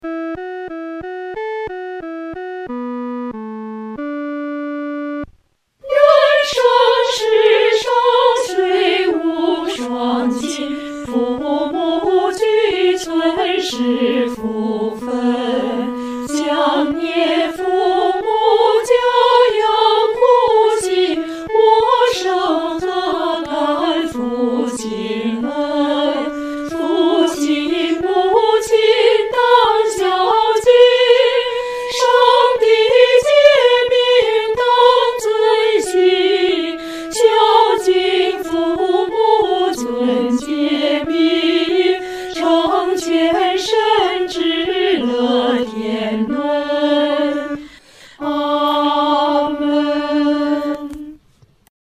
合唱
诗班在二次创作这首诗歌时，要清楚这首诗歌的音乐表情是亲切、温存地。